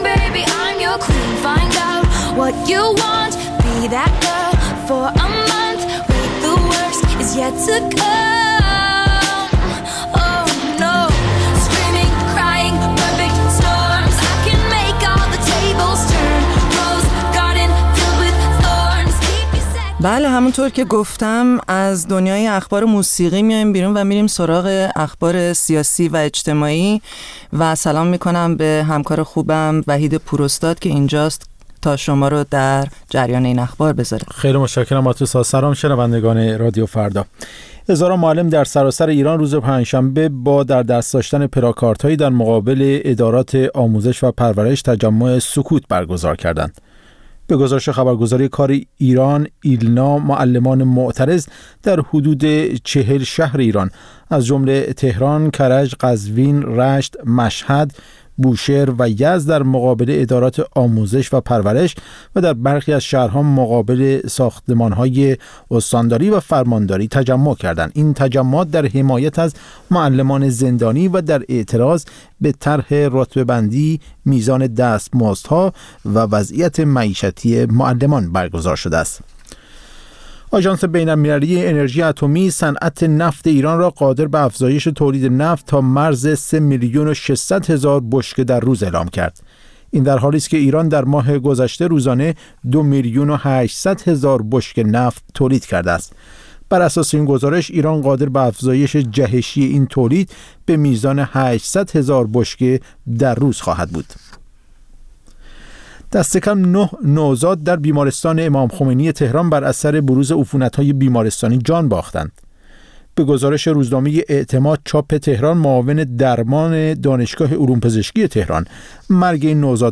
برنامه زنده بخش موسیقی رادیو فردا.